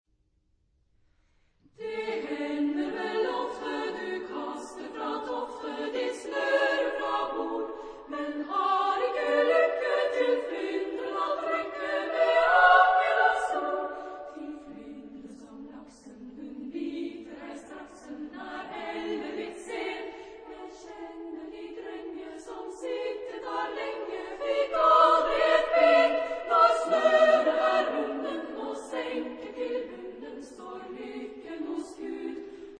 Genre-Style-Form: Popular ; Lied
Mood of the piece: fast
Type of Choir: SSA  (3 women voices )
Tonality: G major